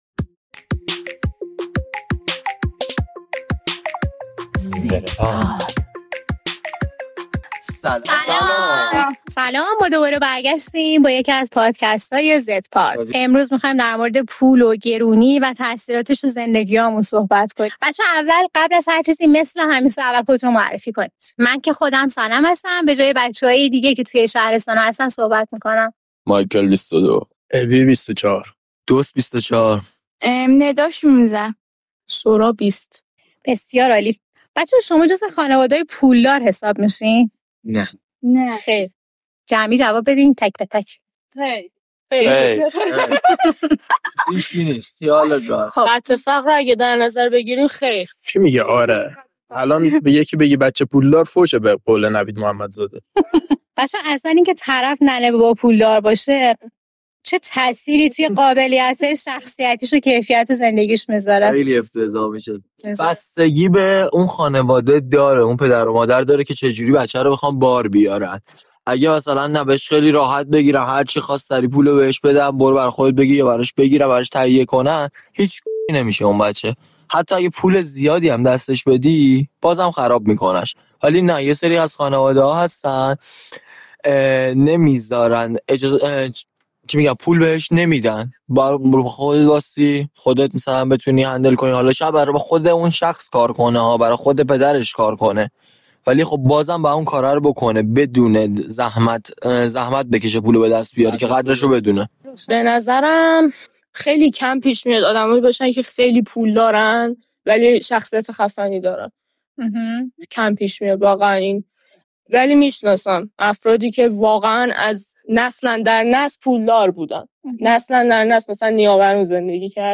در این قسمت پادکست «زدپاد» گروهی از جوانان نسل زد درباره گرانی و تاثیر پول در زندگیشان گفت‌وگو می‌کنند.